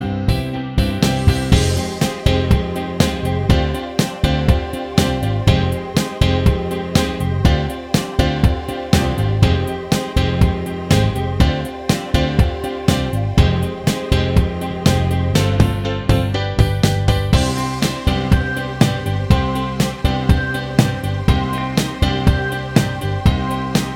Minus Main Guitar Pop (1980s) 4:19 Buy £1.50